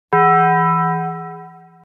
Taco Bell Bong